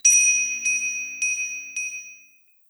snd_ceroba_attack_bell_ring.wav